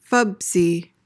PRONUNCIATION: (FUHB-zee) MEANING: adjective: Short and stout; stocky.